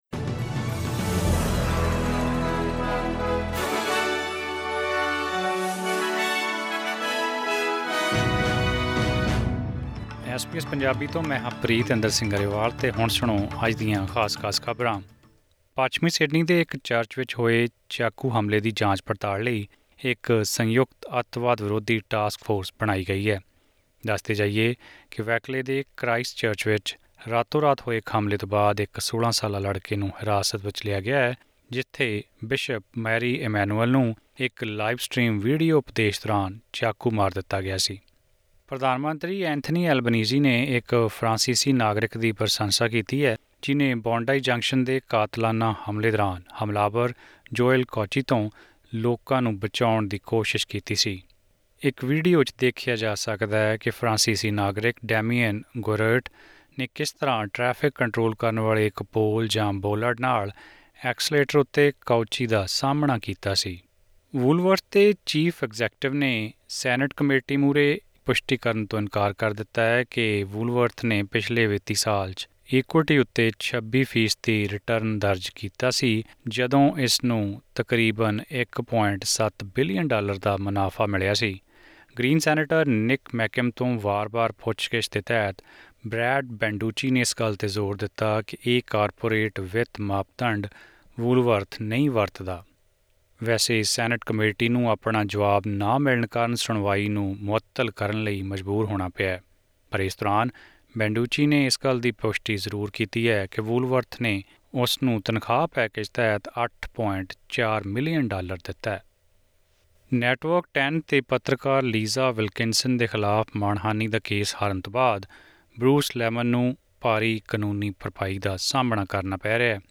ਅੱਜ ਦੀਆਂ ਮੁੱਖ ਰਾਸ਼ਟਰੀ ਅਤੇ ਅੰਤਰਰਾਸ਼ਟਰੀ ਖਬਰਾਂ ਜਾਨਣ ਲਈ ਸੁਣੋ ਐਸ ਬੀ ਐਸ ਪੰਜਾਬੀ ਦੀ ਇਹ ਆਡੀਓ ਪੇਸ਼ਕਾਰੀ...